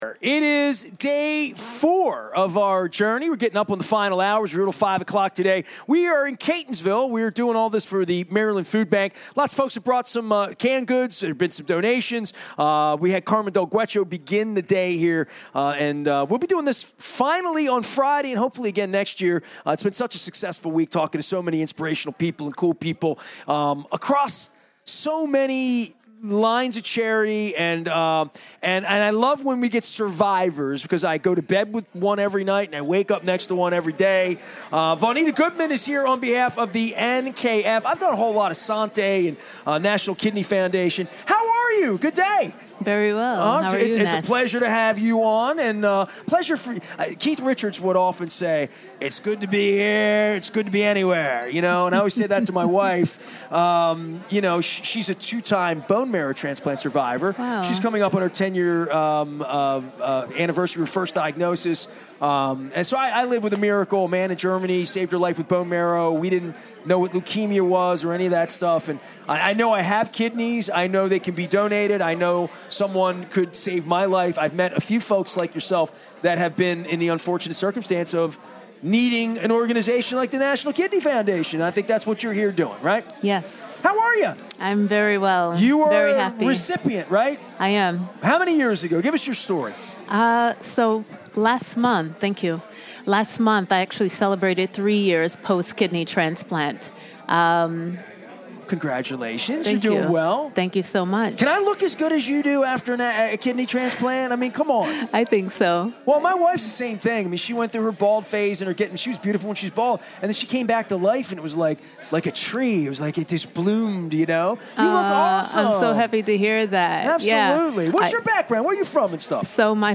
at State Fare in Catonsville